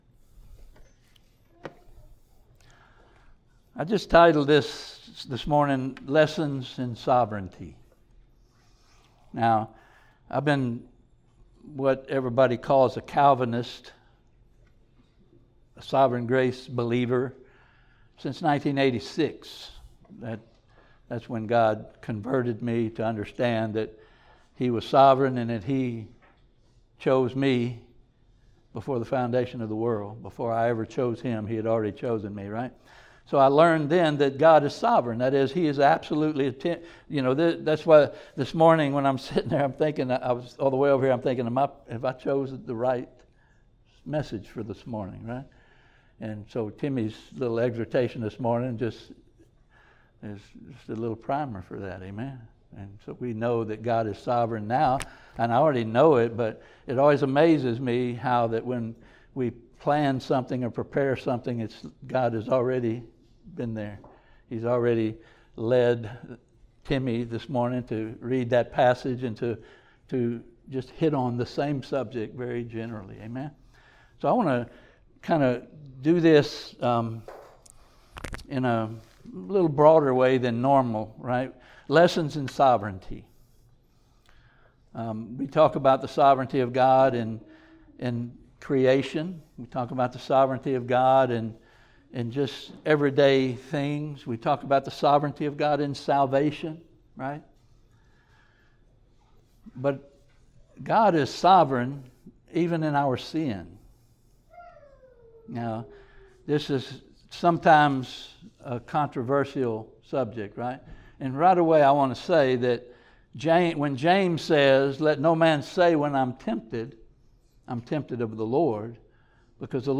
Service Type: Sunday Morning Topics: God's Sovereignty